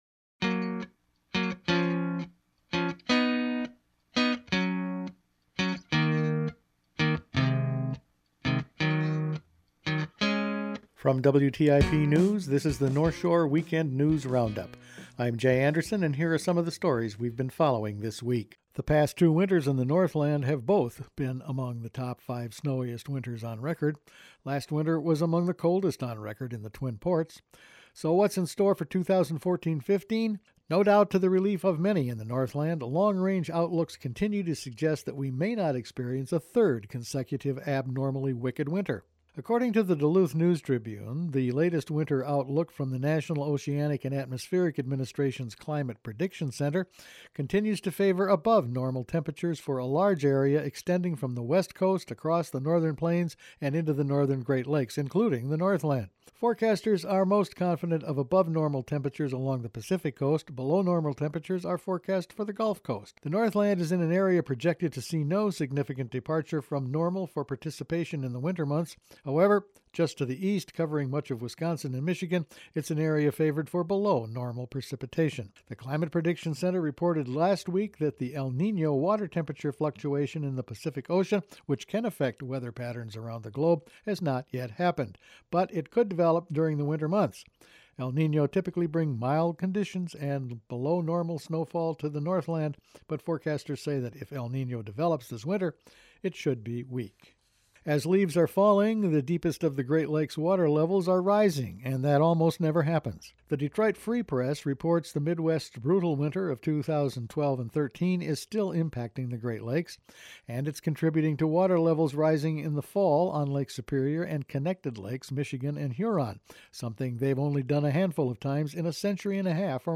Each week the WTIP news department puts together a roundup of the weeks top news stories. Lake Super is colder and deeper than usual, Health organizations want a closer look at copper mining and Legislators talk to the county board…all figured in this week’s news.